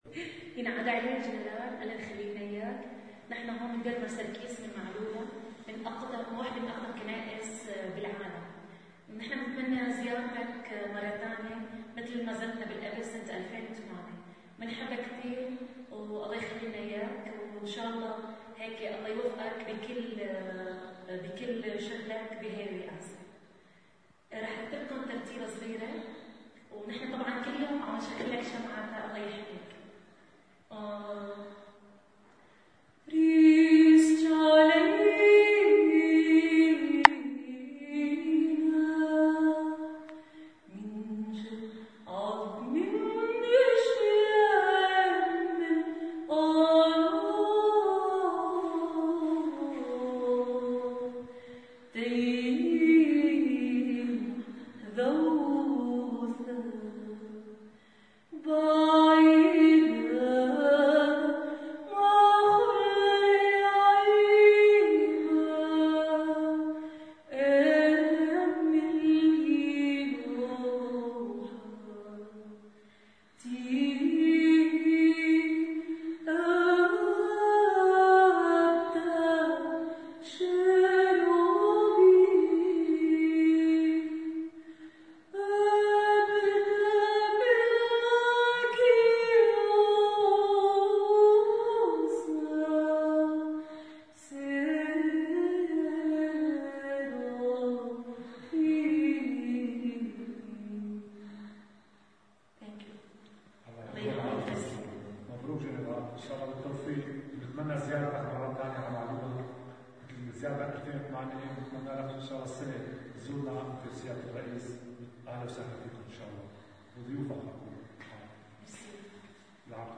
بالفيديو والصوت ترتيلة من معلولا المحررة و.. منضوي شمعة للجنرال ليحميه الله، ونأمل زيارتو مرة تانية لالنا (Video+Audio)